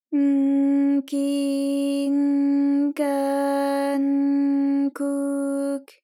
ALYS-DB-001-JPN - First Japanese UTAU vocal library of ALYS.
k_n_ki_n_ka_n_ku_k.wav